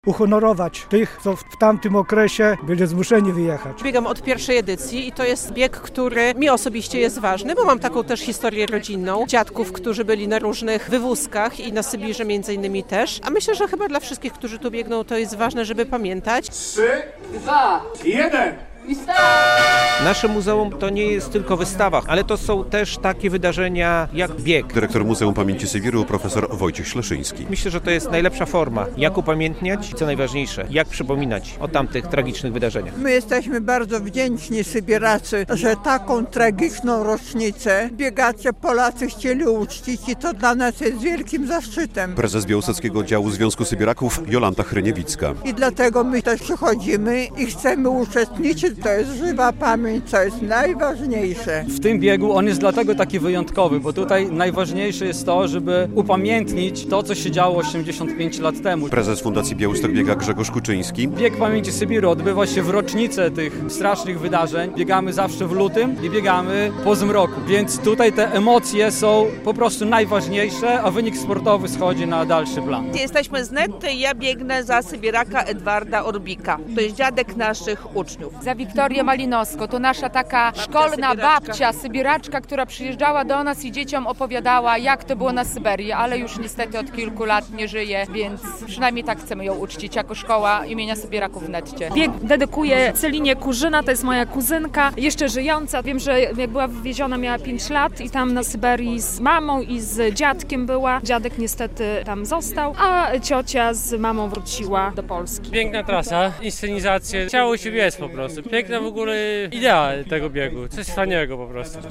Ponad tysiąc biegaczy wzięło udział w VII Biegu Pamięci Sybiru - relacja